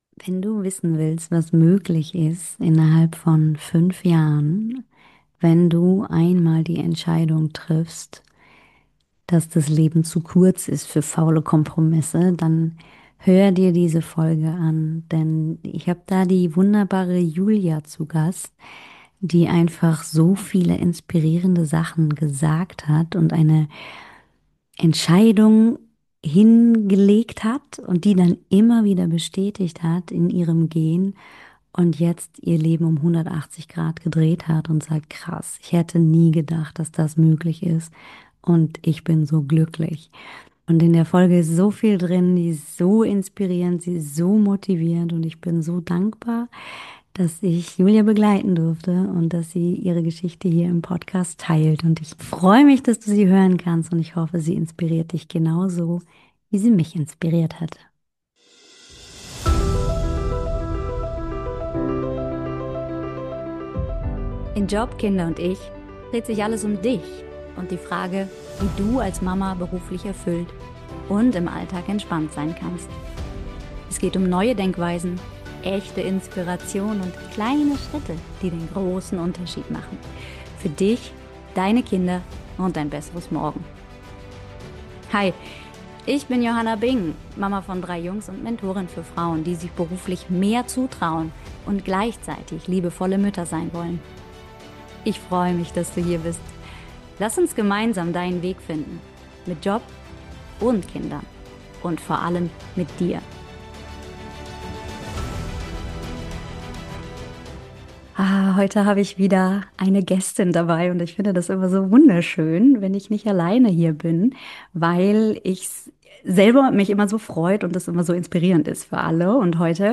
In dieser Interviewfolge bekommst du einen ehrlichen, ermutigenden Einblick in eine Veränderung, die zeigt: Du darfst neu wählen.